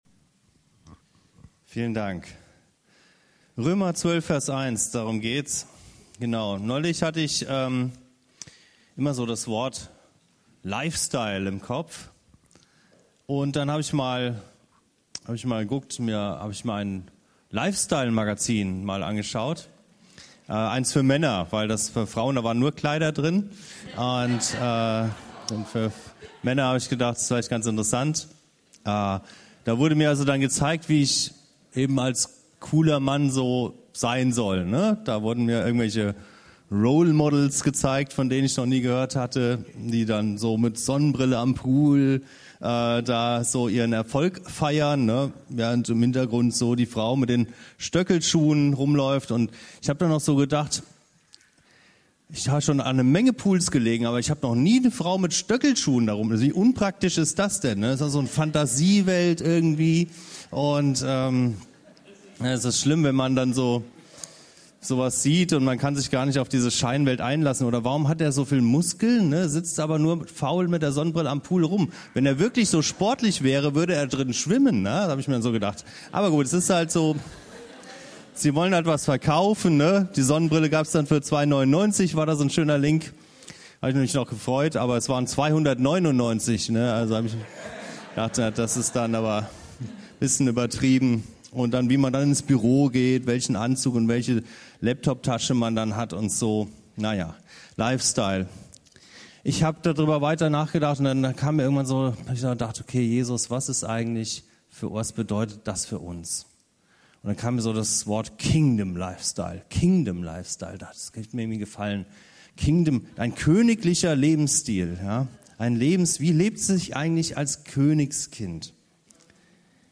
Was bedeutet es als Beschenkte und Geliebte Menschen sich Gott zur Verfügung zu stellen? Was ist der wahre Gottesdienst - zwei schöne Stunden am Sonntag - oder geht es doch mehr um einen Lebensstil? KG-Material zu Predigt